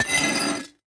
grindstone2.ogg